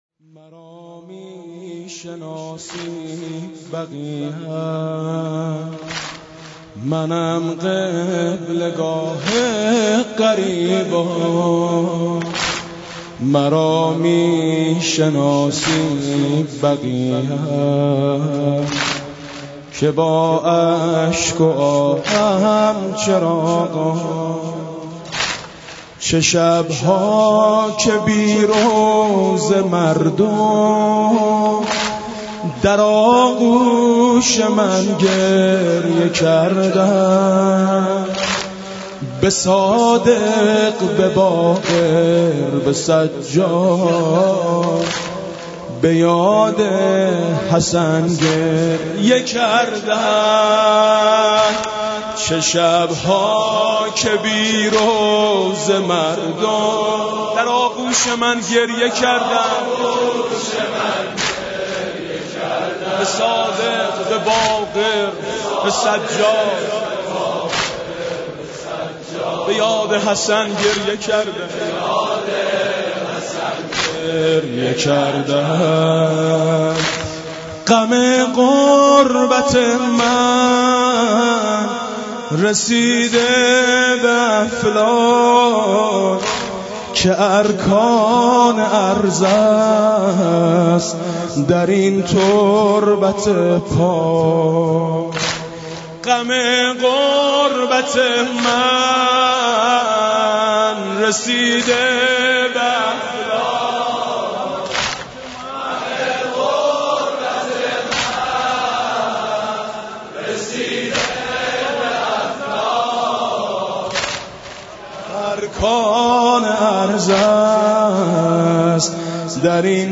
متن سینه زنی واحد شهادت ائمه بقیع (ع) برای امام سجاد(ع) با سبک -( مرا می‌ شناسی بقیعم )